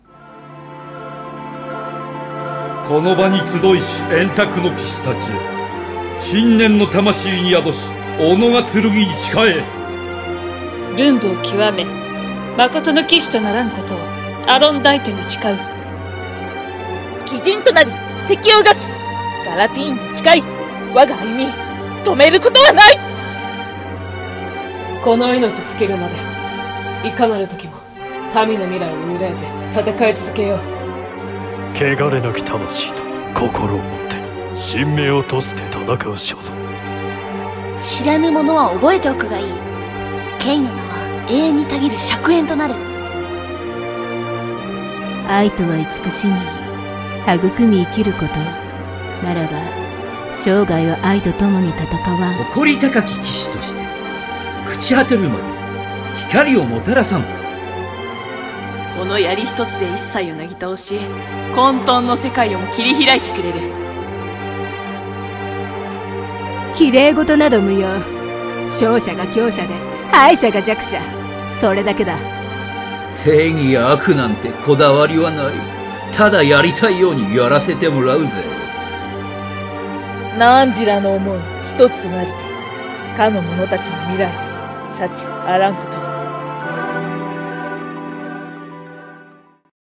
円卓の騎士〜十二の誓い〜〗 【声劇】【十二人用】